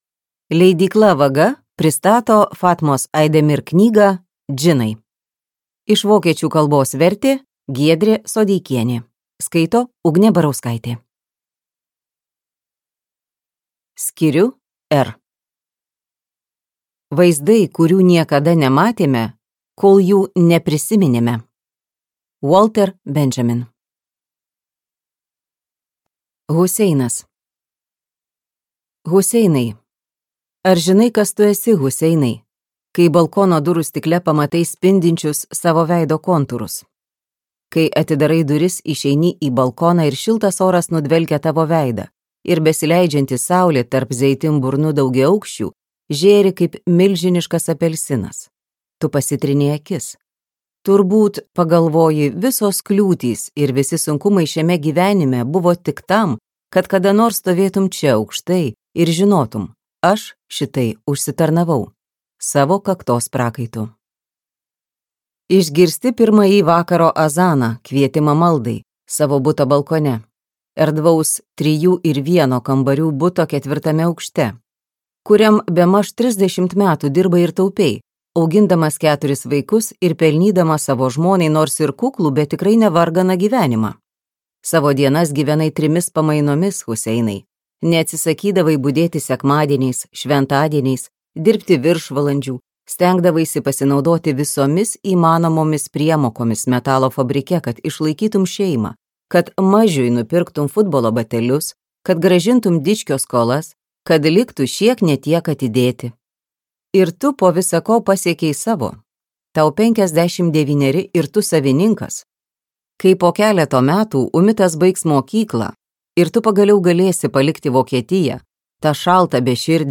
Džinai | Audioknygos | baltos lankos